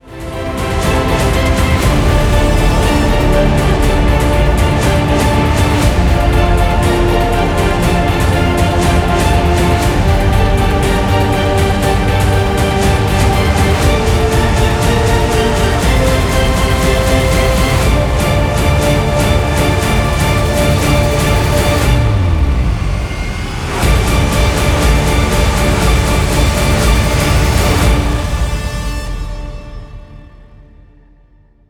• Качество: 320, Stereo
громкие
без слов
инструментальные
эпичные
героические